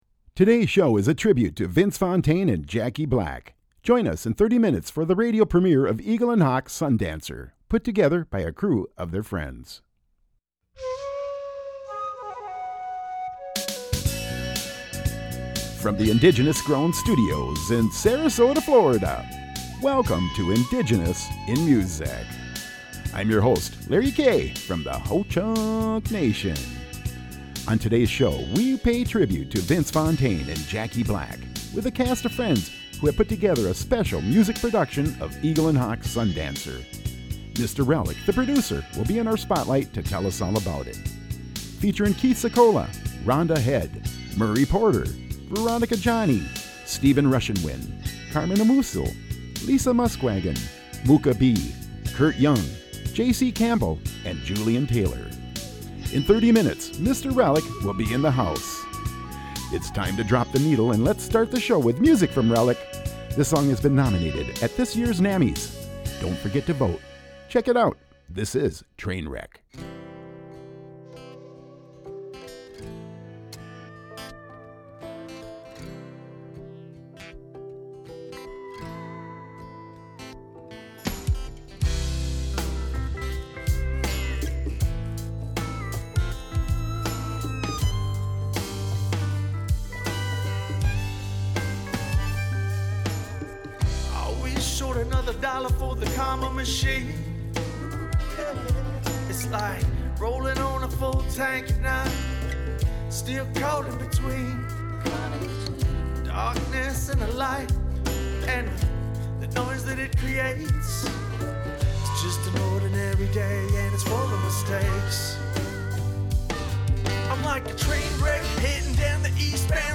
Hour 1 Subtitle: 2 hours of new and original music from our Indigenous, Native, Mexican, Aboriginal musicians of the western hemisphere. Program Type: Weekly Program Speakers: Version: 1 Version Description: Version Length: 12:58 a.m. Date Recorded: Jan. 1, 0001 1: 12:58 a.m. - 68MB download